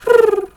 pigeon_2_call_calm_06.wav